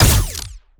GUNAuto_Plasmid Machinegun B Single_04_SFRMS_SCIWPNS.wav